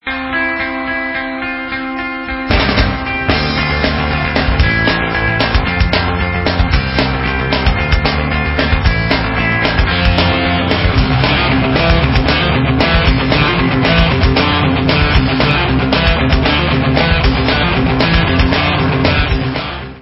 Live in Chicago
Rock/Punk